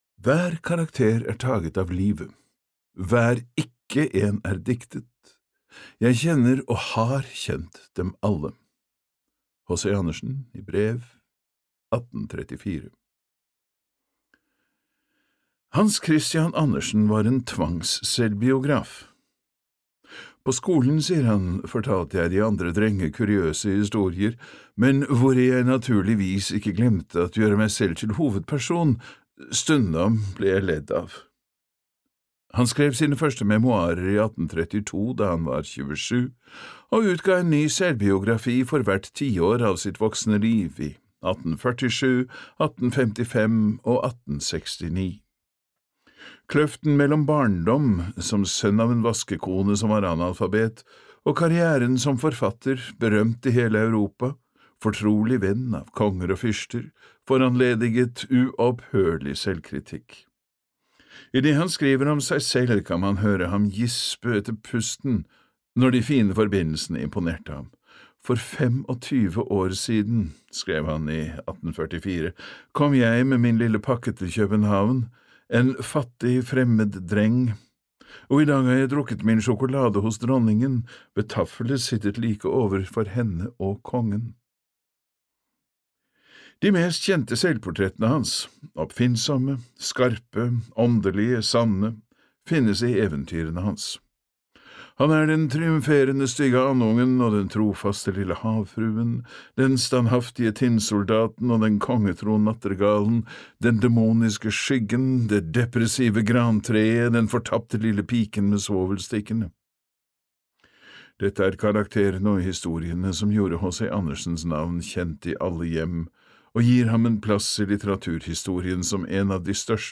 Hans Christian Andersen - en eventyrfortellers liv (lydbok) av Jackie Wullschläger